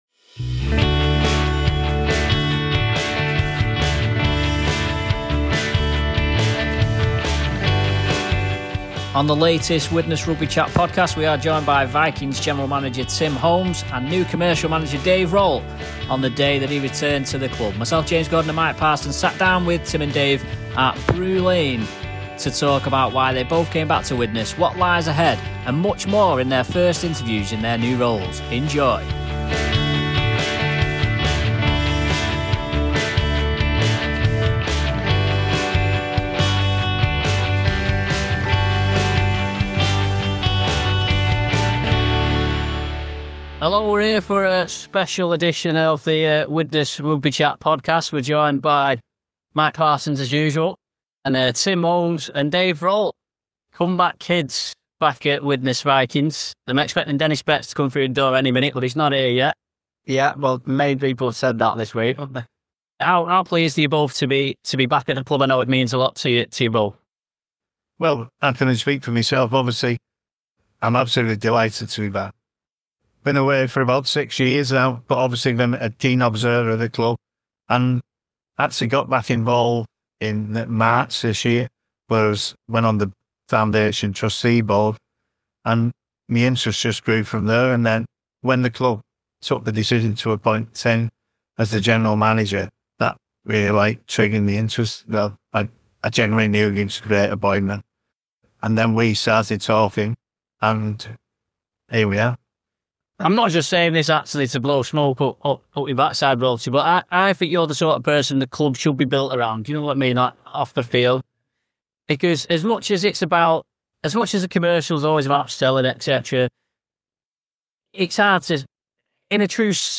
They talk about their reasons for returning and how they’re hoping to make an impact, as well as discussing a number of topics including the state of rugby league, promotion and relegation and much more. *Apologies for the occasional sound issue within the podcast, we had to remove some background noise from the original recording that may have distorted some voices at times, but hopefully all of the show is understandable!*